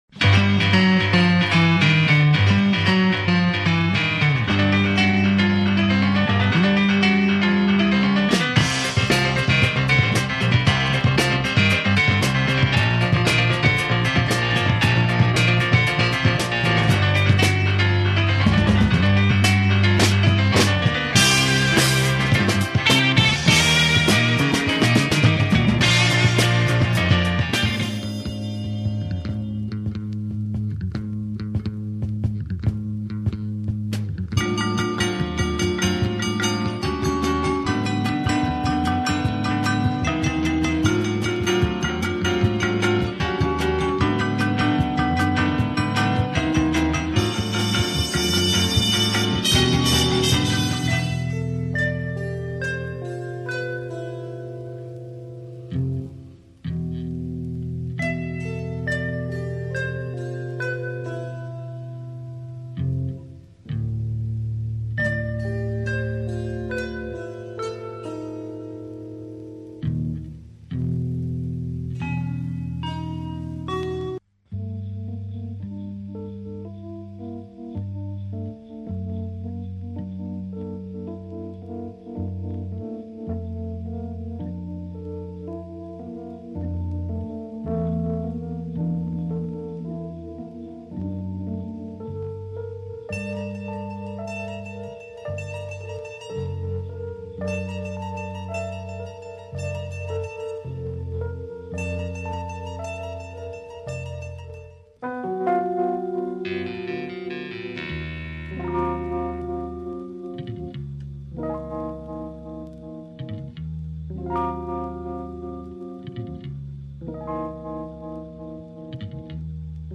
Drama groove and killer samples on this library
Library Music